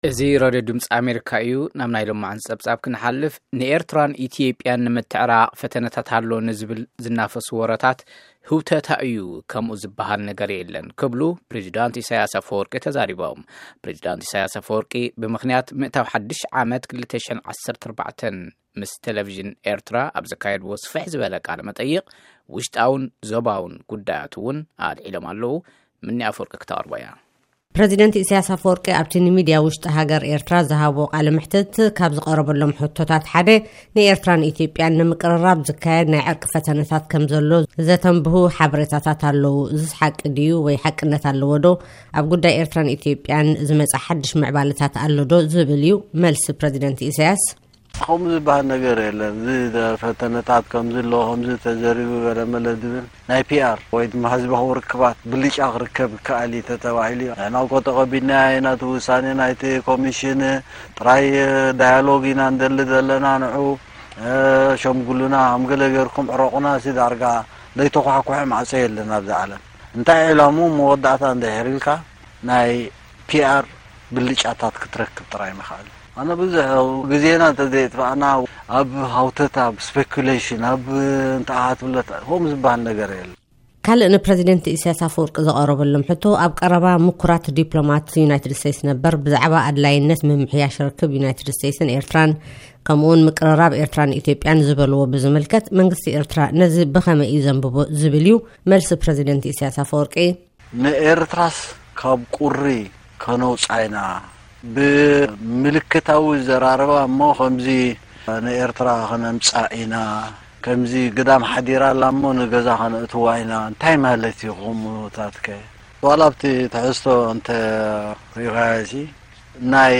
ንኤርትራን ኢትዮጵያን ንምትዕራቅ ፈተነታት'ሎ ዝብል ዝናፈስ ወረታት ህውተታ'ዩ ፣ ከምኡ ዝበሃል ነገር የለን ክብሉ ናይ ኤርትራ ፕረዚደንት ኢሳያስ አፈወርቂ ተዛሪቦም። ፕረዚደንት ኢሳያስ አፈወርቂ ምስ ቴለቪዥን ኤርትራ ብምኽንያት ምእታው ሓድሽ ዓመት 2014 ኣብ ዝተብህለ ኣብ ዝሃብዎ ስፍሕ ዝበለ ቃለ-መሕትት ውሽጣዉን ዞባዉን ጉዳያት'ዉን አልዒሎም'ለዉ። ንምስምዑ ኣብ ታሕቲ ንዘሎ ምልክት ድምፂ ጠውቁ። TIG-Eritrean-President